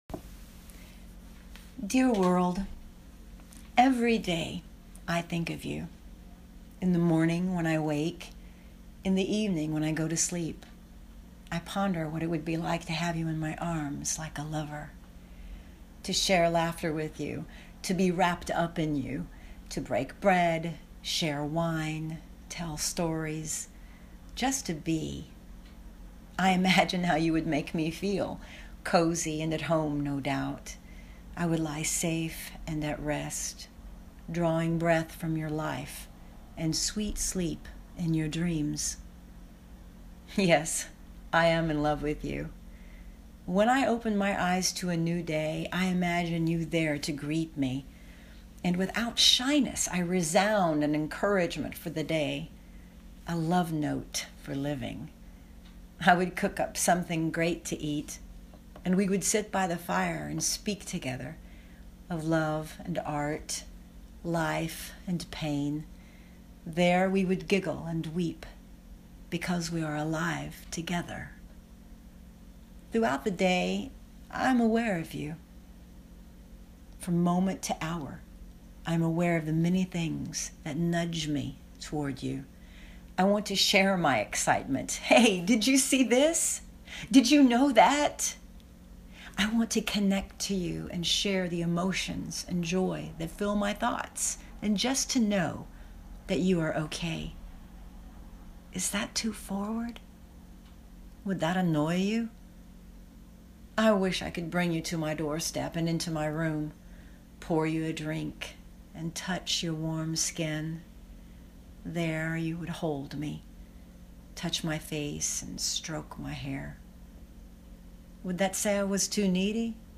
So, with this special Valentine’s Day post I decided to include a recording of me reciting the poem.